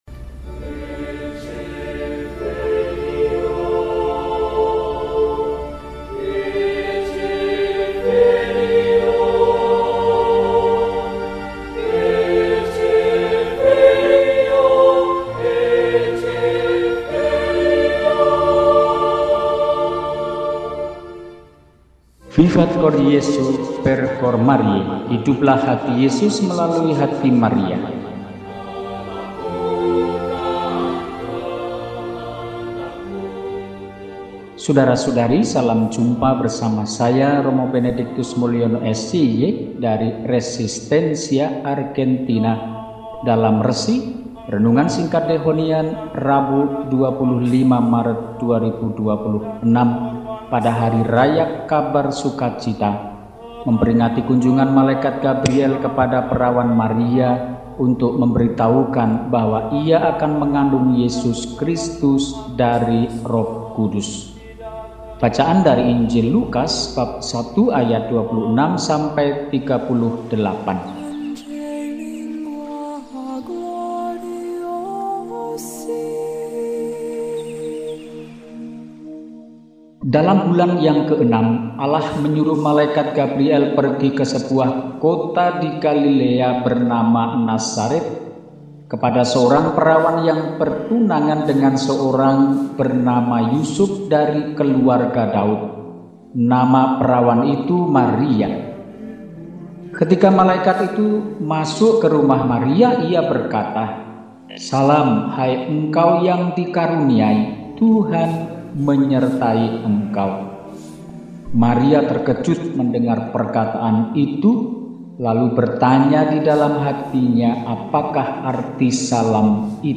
Rabu, 25 Maret 2026 – HARI RAYA KHABAR SUKACITA – RESI (Renungan Singkat) DEHONIAN